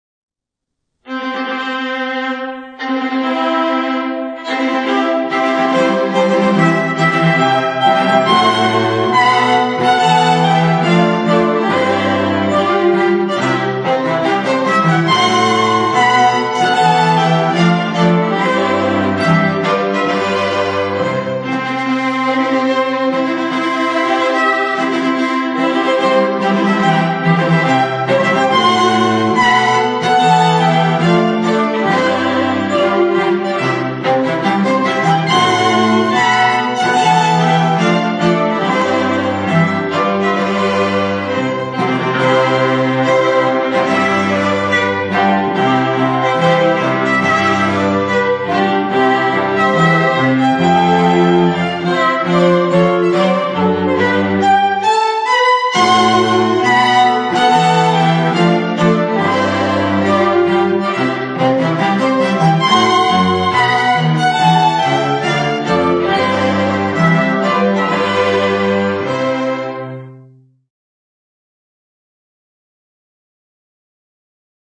• Versatile, eclectic string quartet
A second violin, a viola and a 'cello complete the standard line-up.